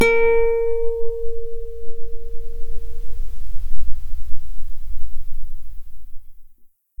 A#4_mf.mp3